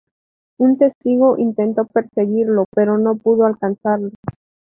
tes‧ti‧go
/tesˈtiɡo/